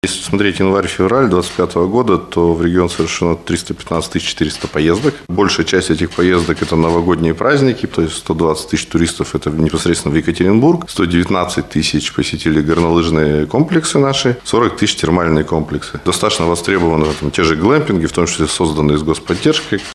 Данные за январь и февраль привел на пресс-конференции «ТАСС-Урал» заместитель губернатора Дмитрий Ионин. Он отметил, что большая часть туристов посетили регион в новогодние праздники.